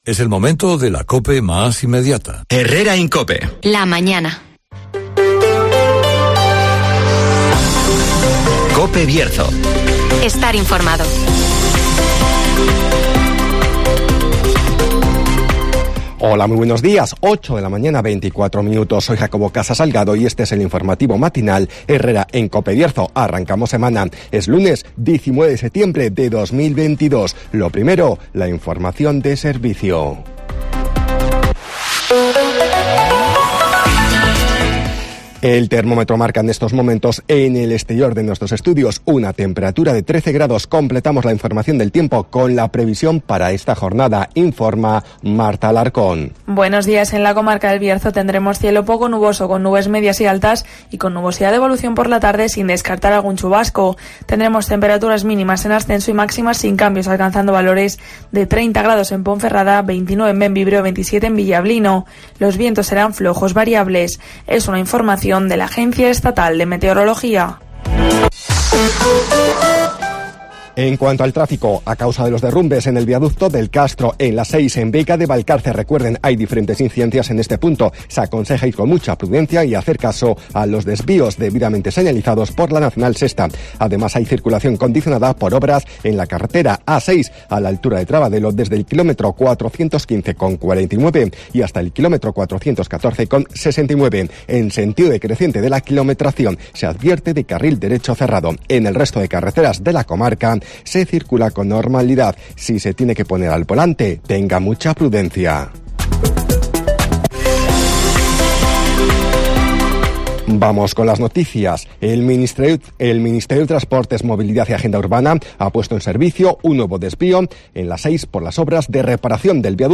INFORMATIVOS